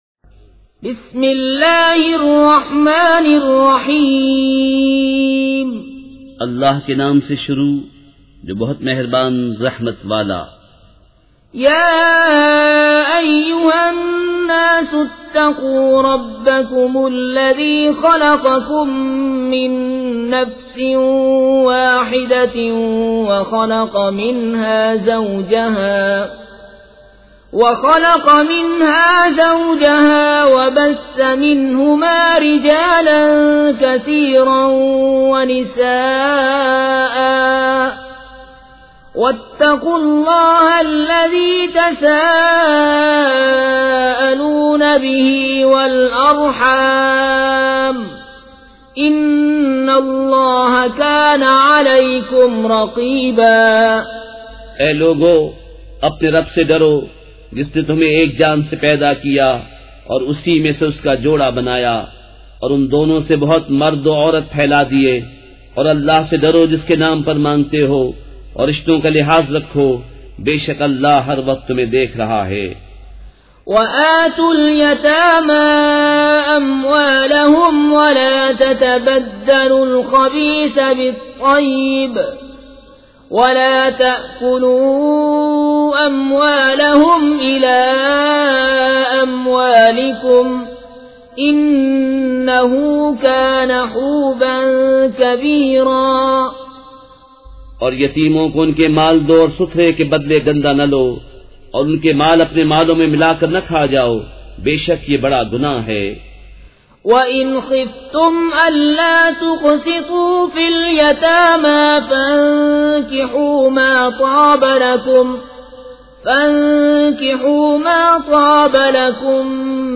سورۃ النساء مع ترجمہ کنزالایمان ZiaeTaiba Audio میڈیا کی معلومات نام سورۃ النساء مع ترجمہ کنزالایمان موضوع تلاوت آواز دیگر زبان عربی کل نتائج 5049 قسم آڈیو ڈاؤن لوڈ MP 3 ڈاؤن لوڈ MP 4 متعلقہ تجویزوآراء